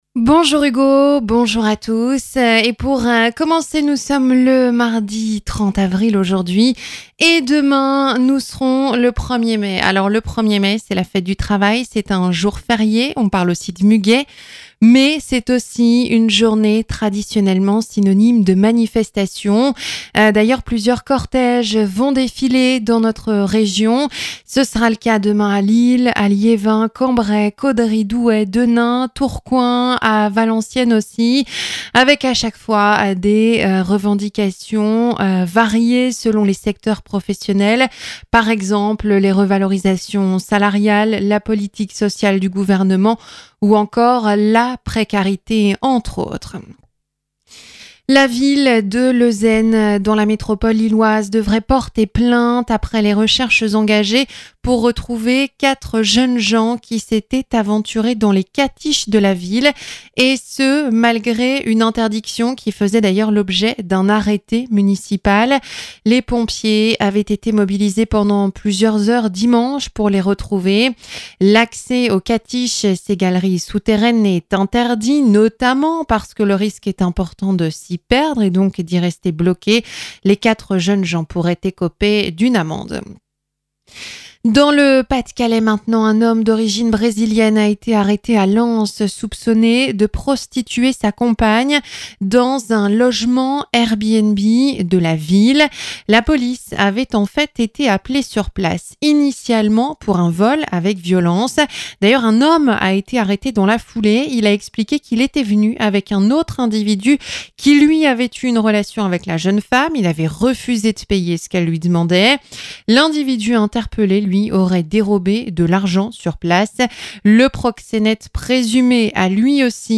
Journal 9h - Plusieurs cortèges demain, 1er mai, dans la Région